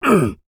Male_Grunt_Hit_01.wav